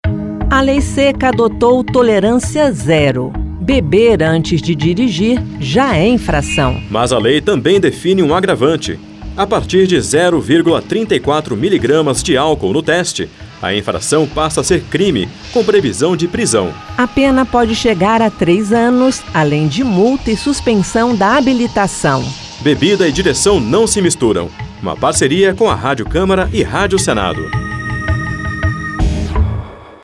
Spots e Campanhas